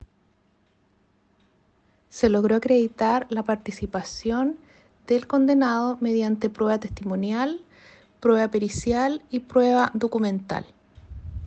Fiscal Claudia Baeza